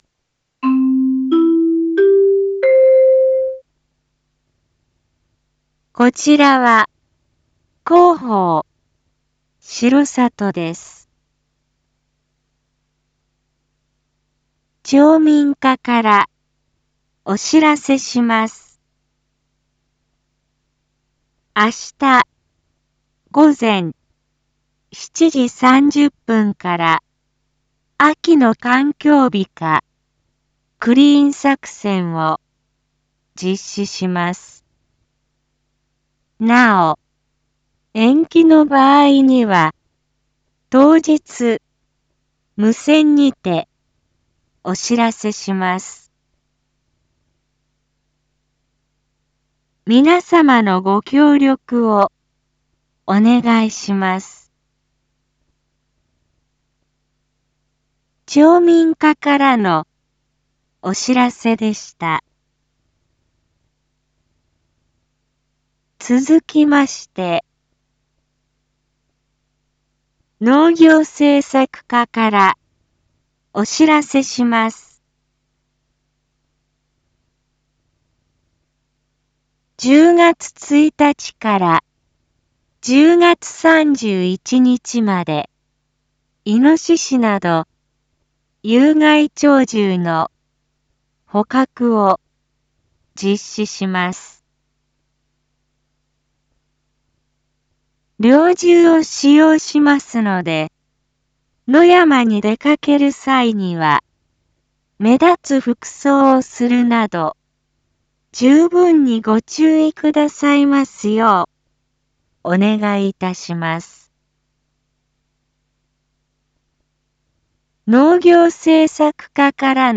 Back Home 一般放送情報 音声放送 再生 一般放送情報 登録日時：2023-10-21 19:02:19 タイトル：有害鳥獣捕獲について インフォメーション：こちらは、広報しろさとです。